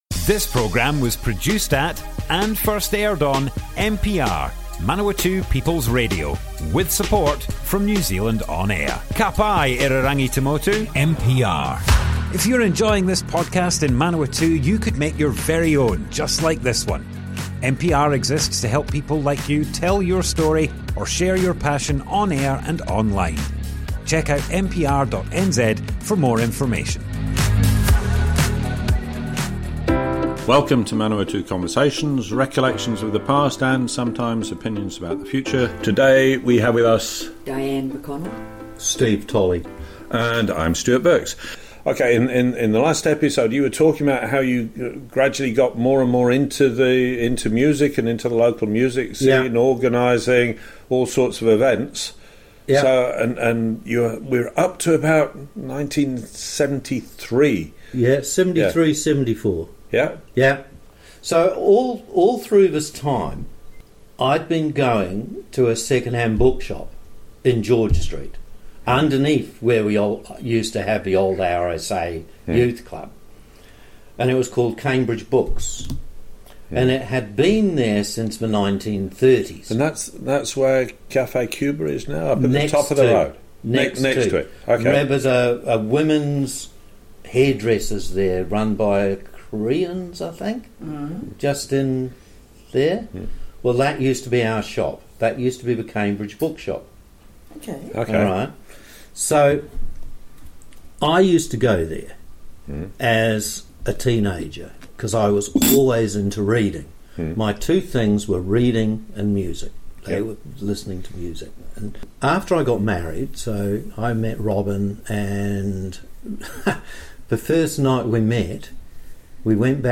Manawatu Conversations More Info → Description Broadcast on Manawatu People's Radio, 2nd September 2025.
oral history